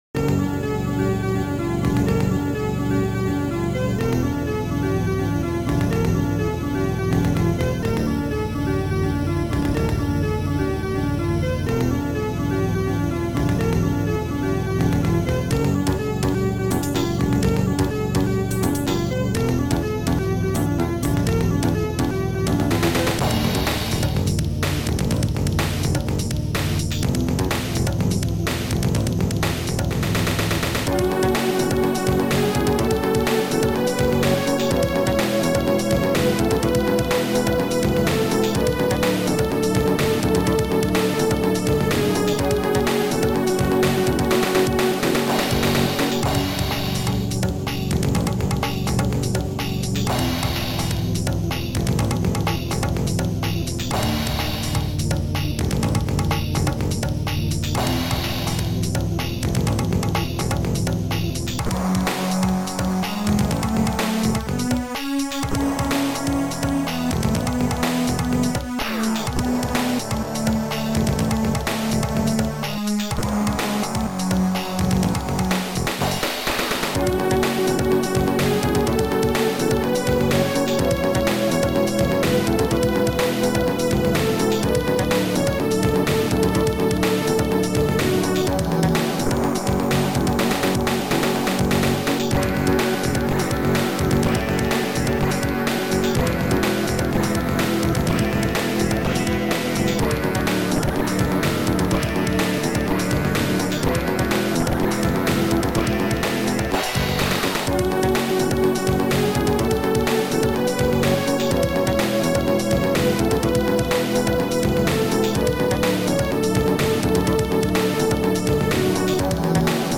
Sound Format: Noisetracker/Protracker